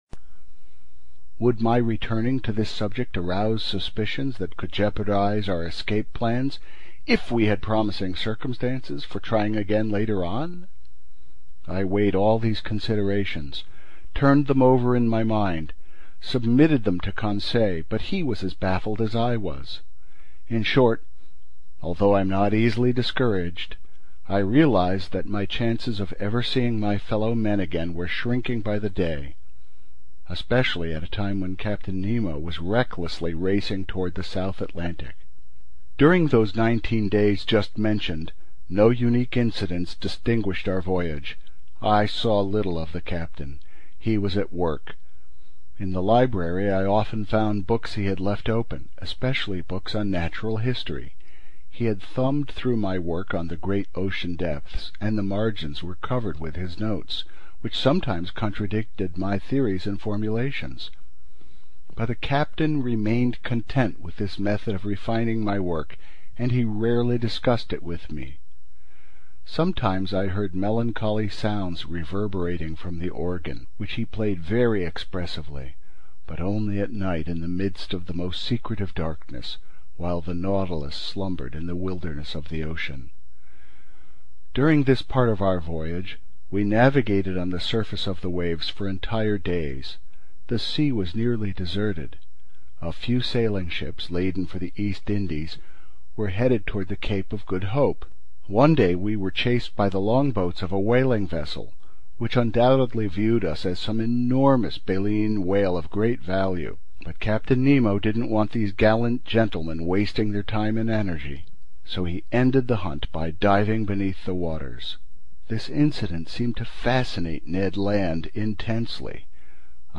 英语听书《海底两万里》第407期 第25章 地中海四十八小时(39) 听力文件下载—在线英语听力室